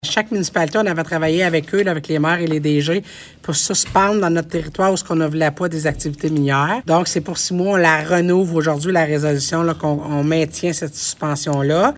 Voici les propos de la préfète de la Vallée-de-la-Gatineau, Chantal Lamarche :